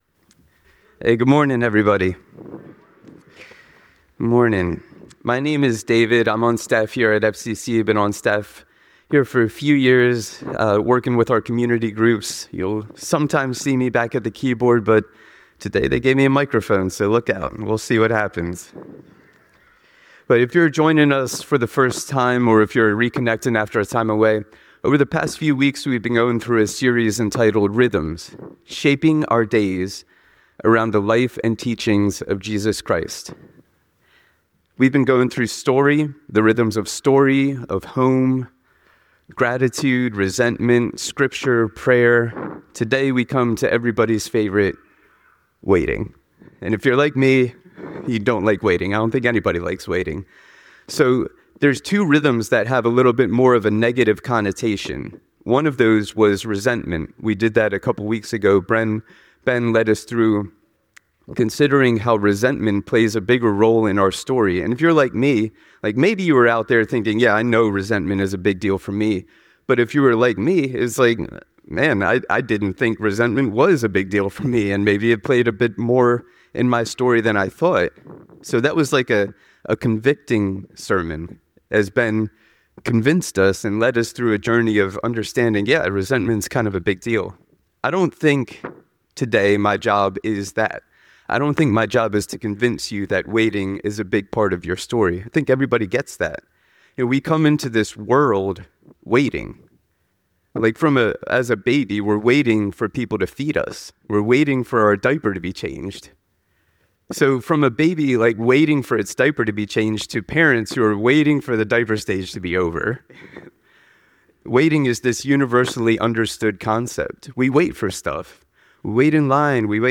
The sermon concludes with a prayer for spiritual alignment and openness to God's guidance during times of waiting.